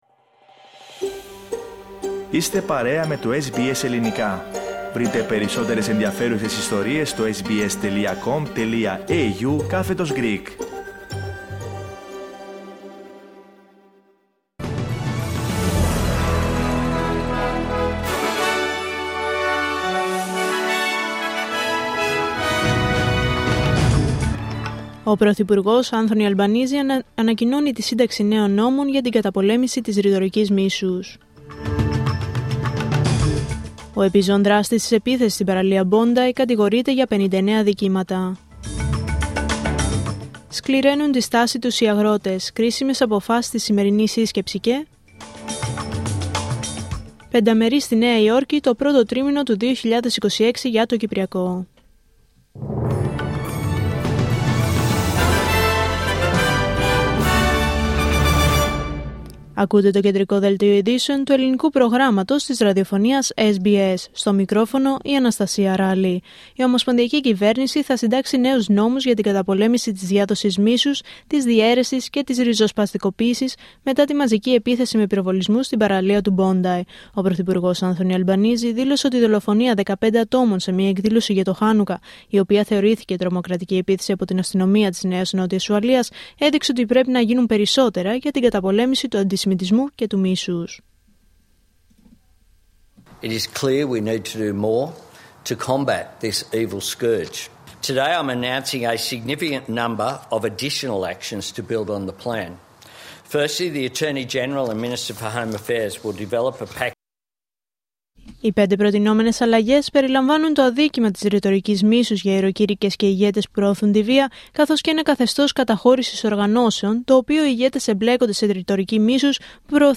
Δελτίο Ειδήσεων Πέμπτη 18 Δεκεμβρίου 2025